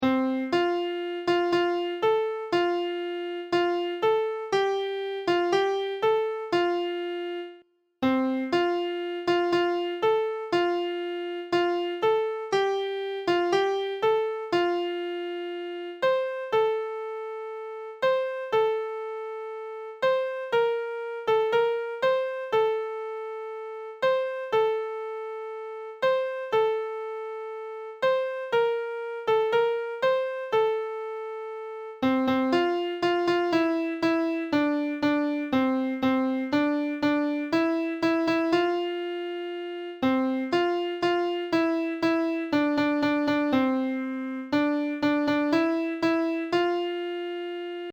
3 Part round